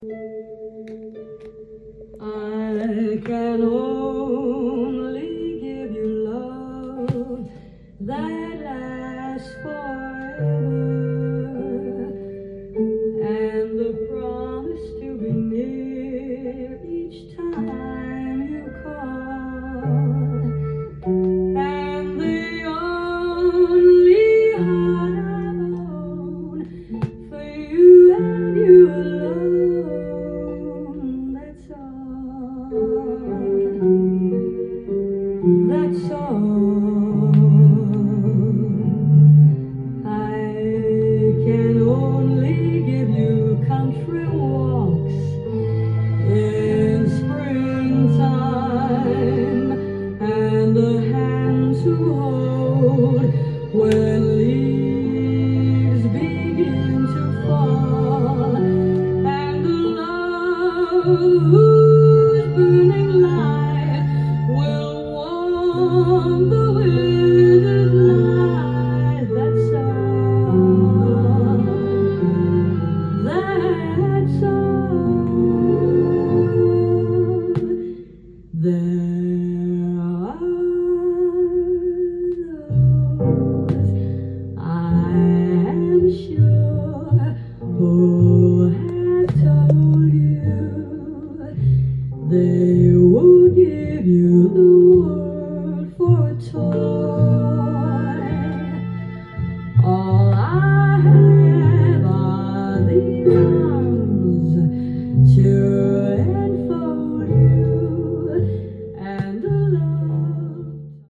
店頭で録音した音源の為、多少の外部音や音質の悪さはございますが、サンプルとしてご視聴ください。
似通った曲調のスローバラード３曲並びが素晴らしい